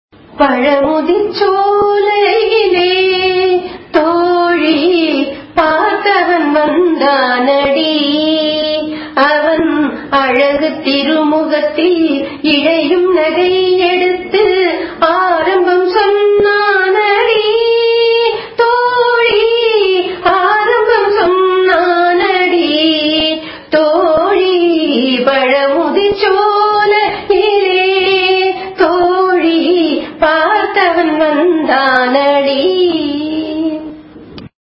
Janyam of 22nd mela Karaharapriya.